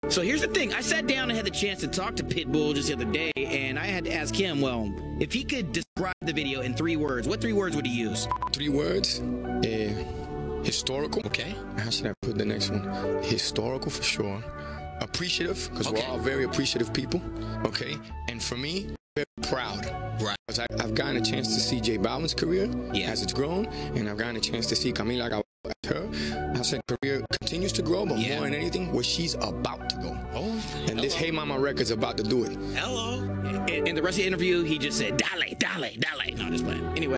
A short Pitbull interview aired on Z100 last night, where he was asked to describe his newest song, “Hey Ma” in three words!
hey-ma-z100-interview.m4a